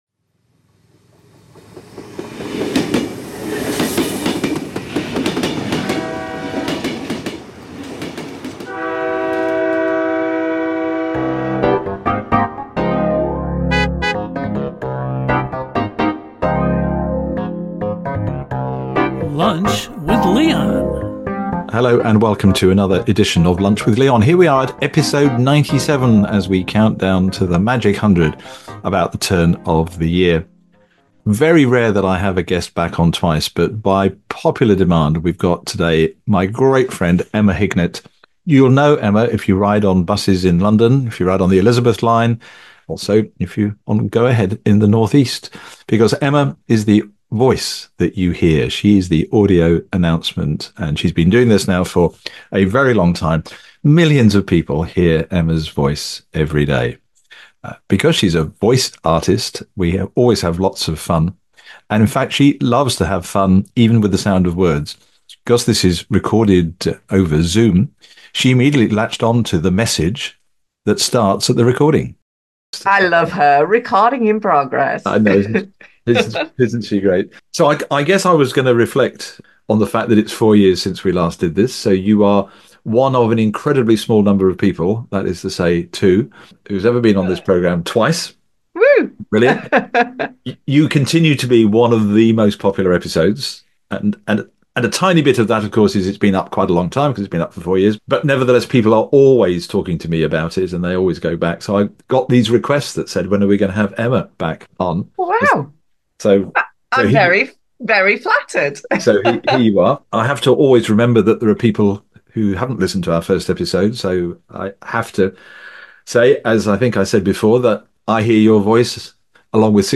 Listen to the end for a bonus musical surprise!